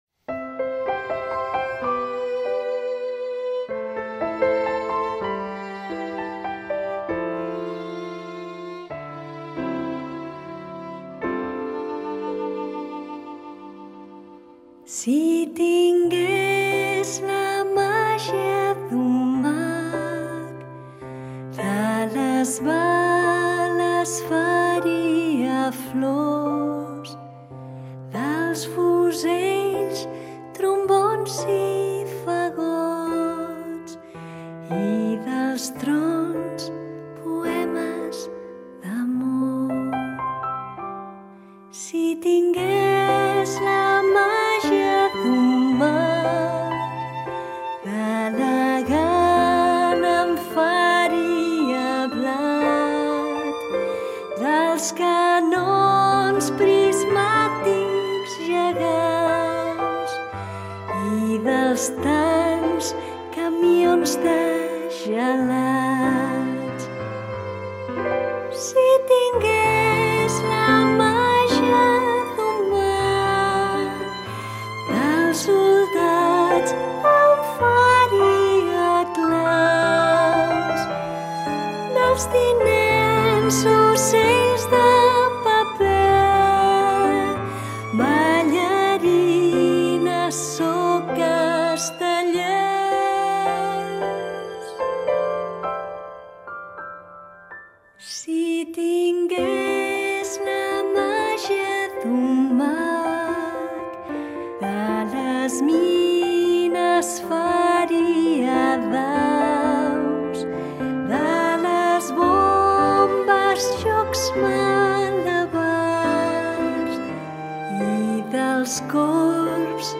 Als nens de la classe dels elefants els agrada molt aquesta cançó per relaxar-se.